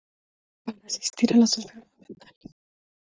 Read more to attend (an event) to assist Frequency C1 Hyphenated as a‧sis‧tir Pronounced as (IPA) /asisˈtiɾ/ Etymology Borrowed from Latin assistō In summary Borrowed from Latin assistere.